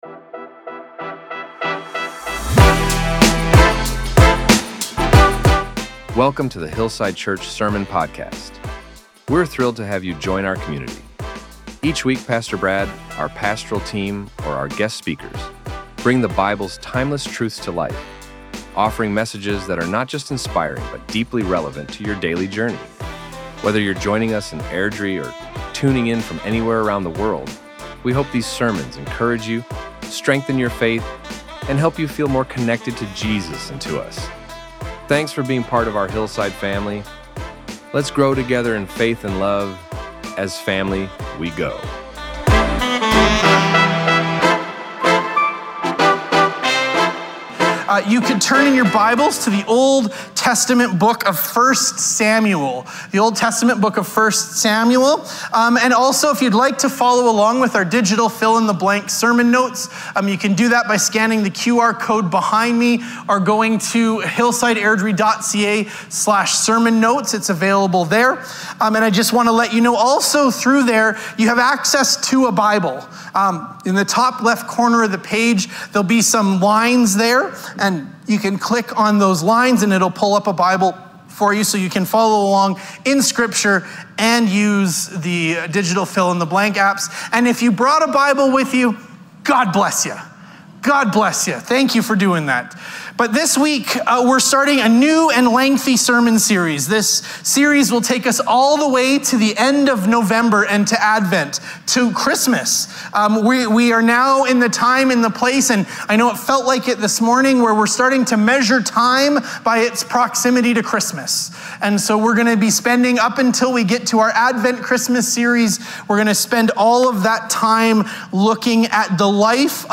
Our Sermons | HILLSIDE CHURCH
This Sunday at Hillside, we kicked off a brand-new sermon series, “A Heart Like His,” as we dive into the life of David.